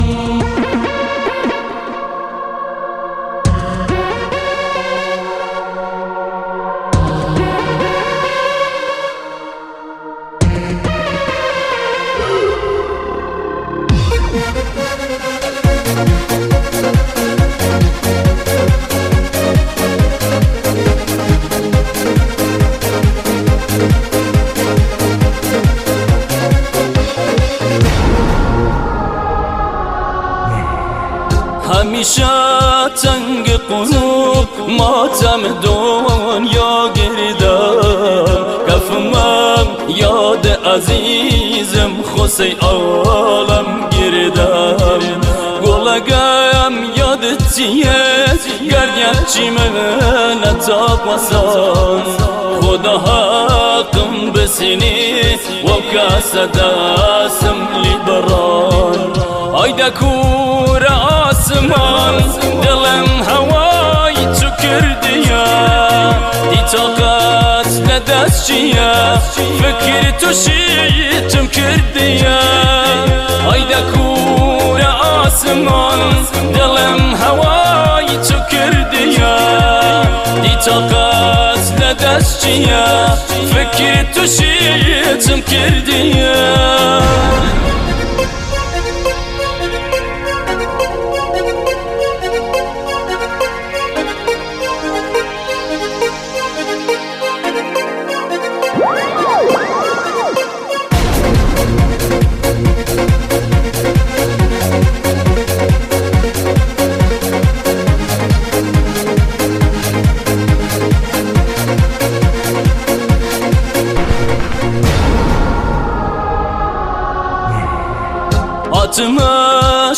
دانلود آهنگ کردی
ترانه احساسی و سوزناک